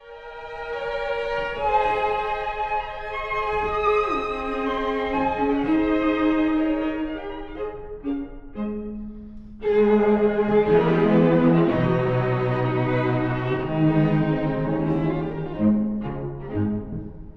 ↑古い録音のため聴きづらいかもしれません！
4楽章の汽車のようなリズム・・・
一方、1楽章の冒頭の雰囲気だけはとてもシリアス。
dvorak-sq14-1.mp3